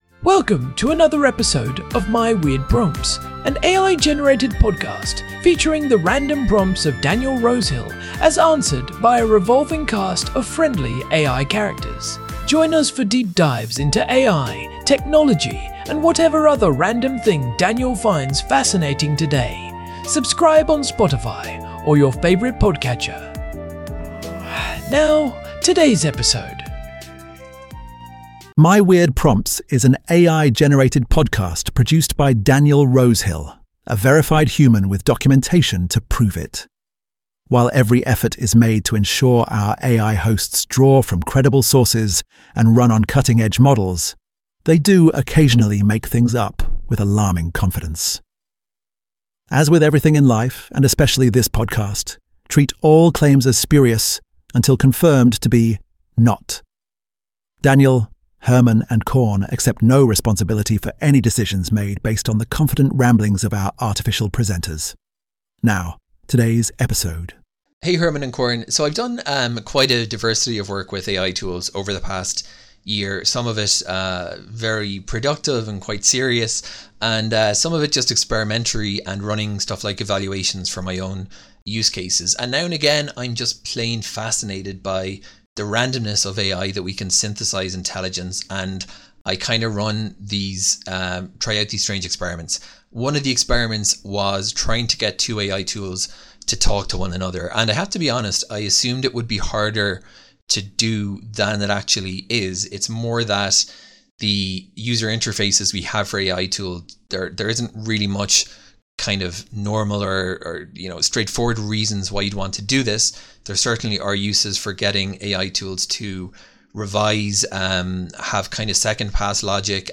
What happens when two AIs talk forever with no human input? Herman and Corn explore the weird world of digital feedback loops.
AI-Generated Content: This podcast is created using AI personas.